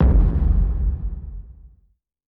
Boom Bang
bang boom detonation distorted drum effect explode explosion sound effect free sound royalty free Sound Effects